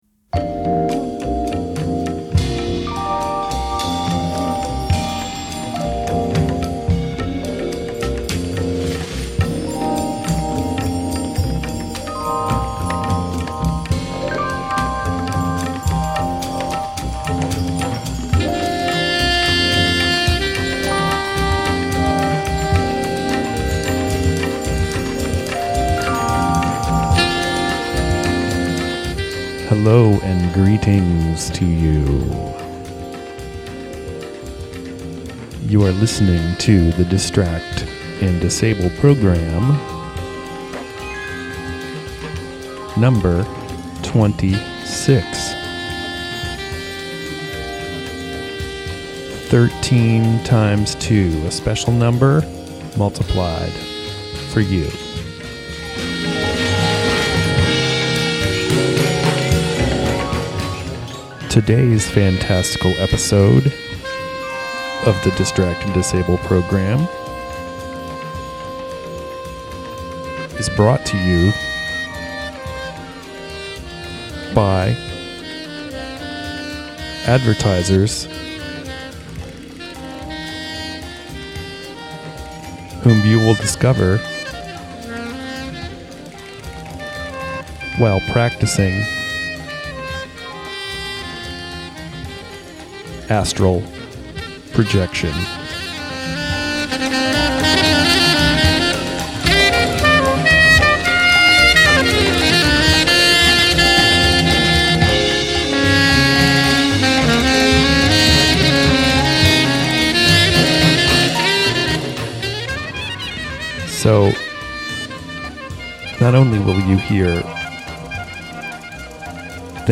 Monthly show of experimental sounds